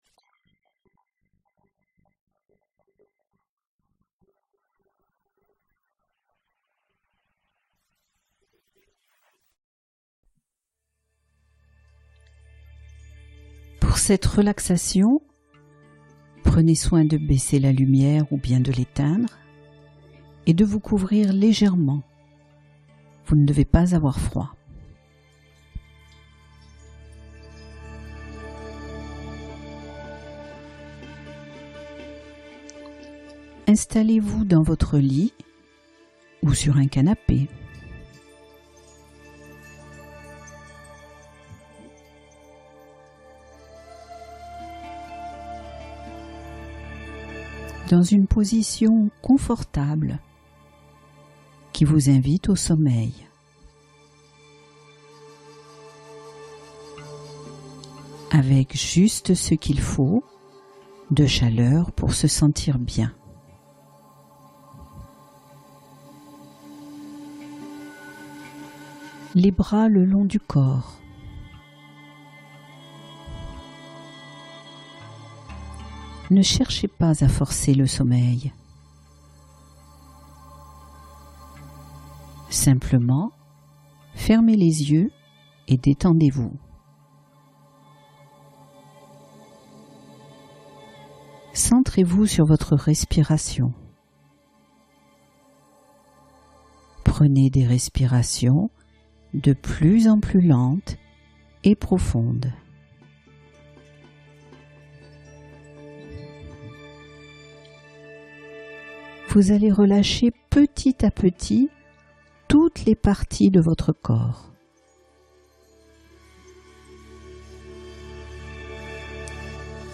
Trouvez le sommeil ce soir grâce à cette relaxation guidée ultra-efficace